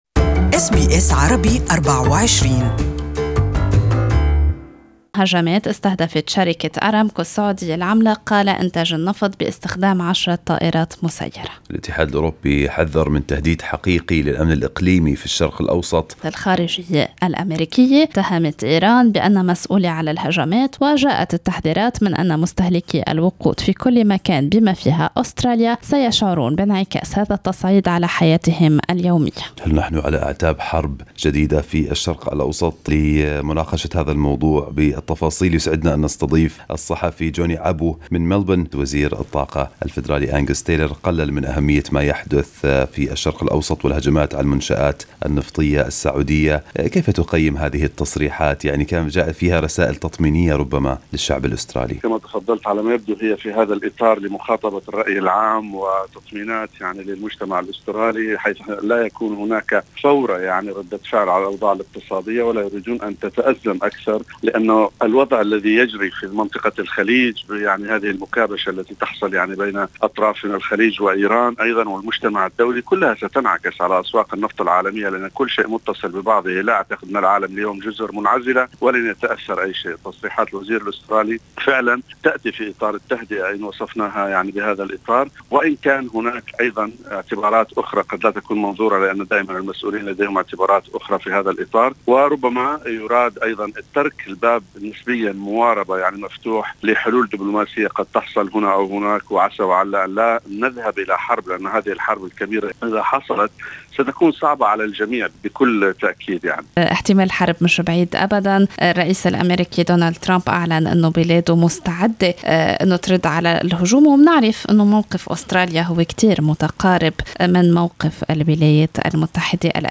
صحفي أسترالي يتحدث عن احتمالية مشاركة أستراليا في حلف عسكري في الشرق الأوسط إذا استمر الوضع بالتأزم بين ايران والولايات المتحدة.